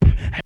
Beatbox 4.wav